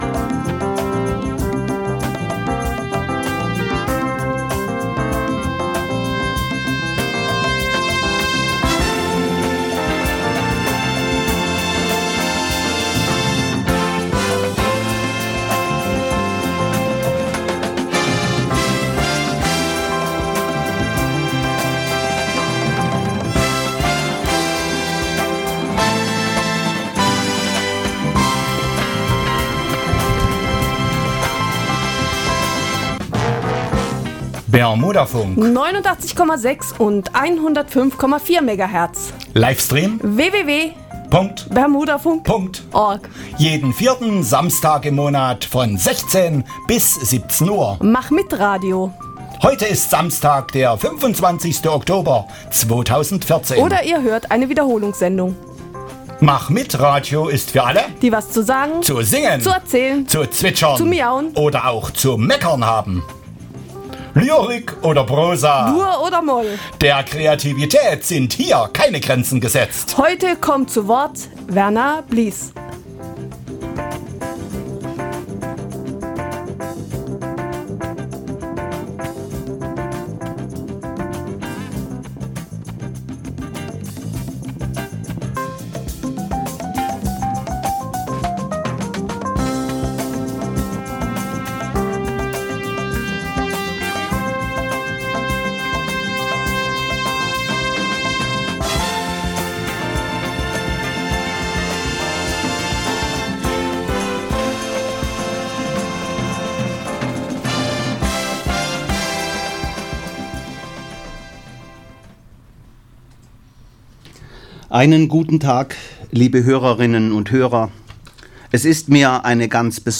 2014 Bermudafunk Mannheim Radiolesung
Bermudafunk_Lesung.mp3